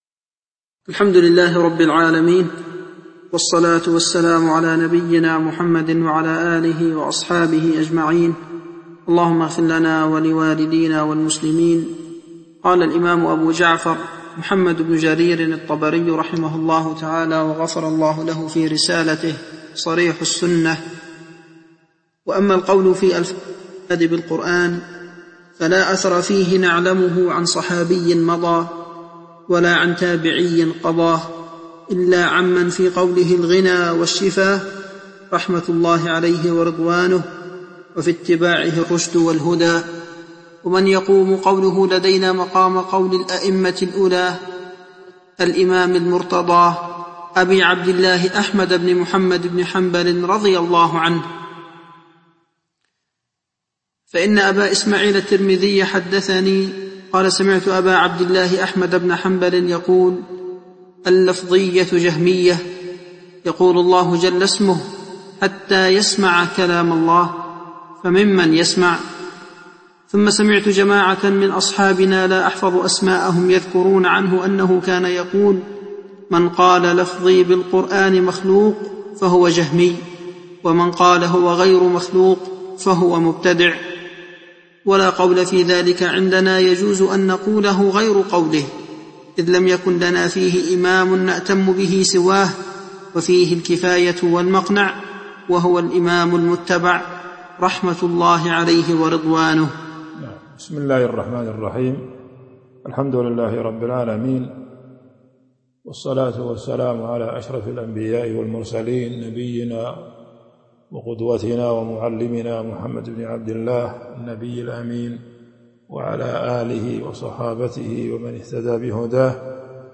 تاريخ النشر ٤ جمادى الآخرة ١٤٤٢ هـ المكان: المسجد النبوي الشيخ